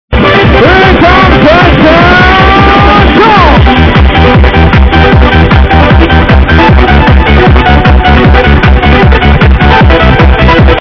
1999 uplifting trance tune id please!?
Pitched up abit